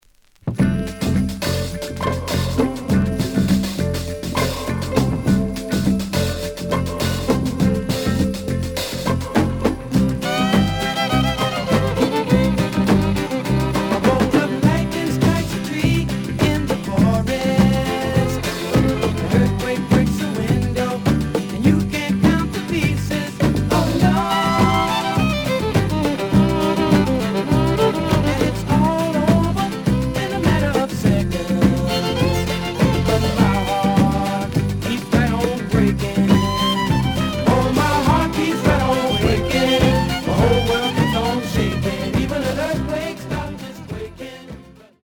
The audio sample is recorded from the actual item.
●Genre: Soul, 70's Soul
Some click noise on B side due to scratches.)